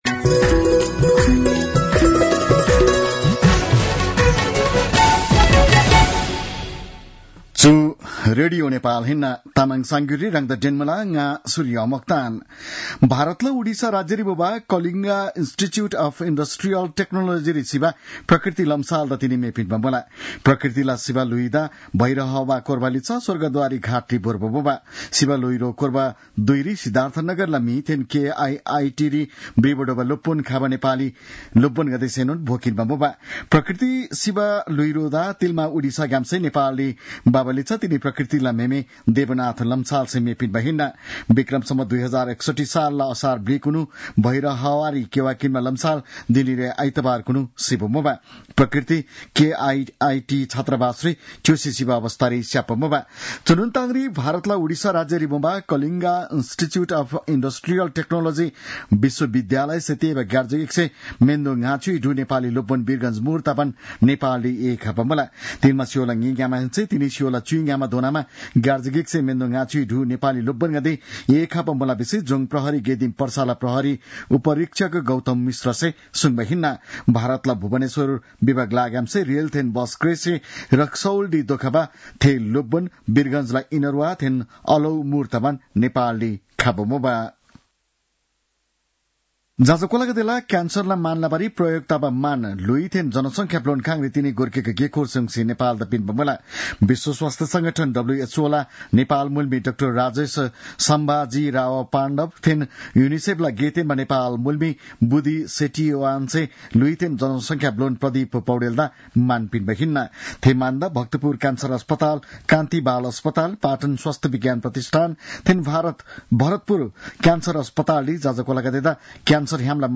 तामाङ भाषाको समाचार : ९ फागुन , २०८१